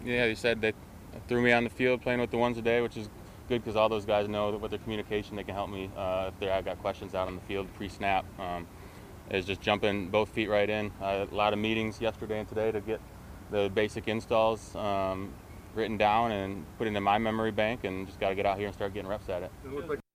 Schobert addressed the media following practice and said that he took part in 11-on-11 drills when he arrived at camp and said that the rest of the team communicated things well and made his first practice in the black and gold easier.